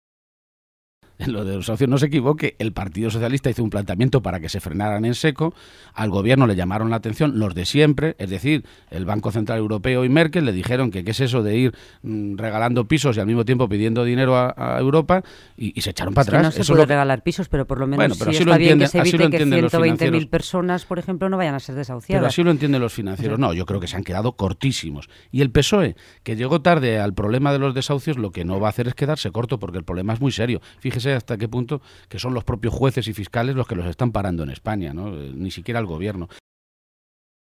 Emiliano García-Page, durante su entrevista en RNE
Cortes de audio de la rueda de prensa